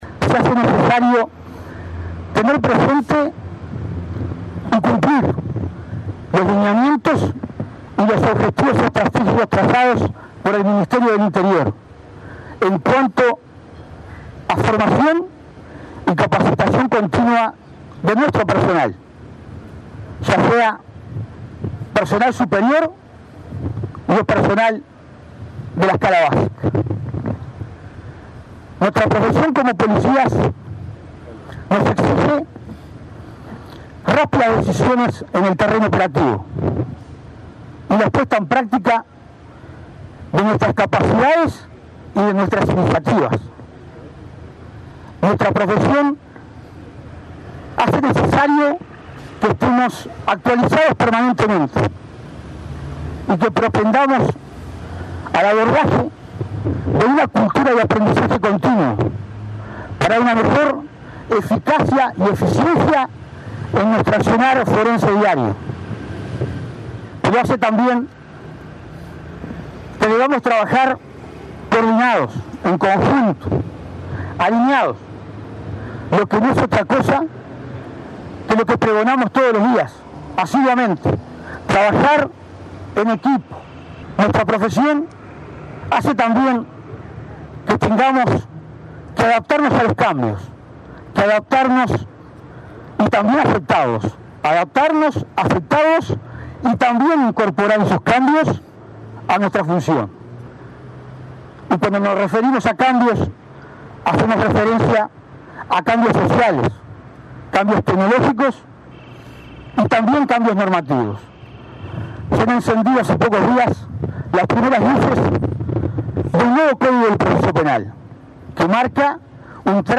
En el Día del Policía Caído en Cumplimiento del Deber, el subdirector de la Policía, Hugo De León, subrayó que los efectivos siempre deben cumplir los lineamientos y objetivos del Ministerio del Interior sobre formación y capacitación. Expresó que deben aceptar los cambios sociales, tecnológicos y normativos por el bienestar de las personas.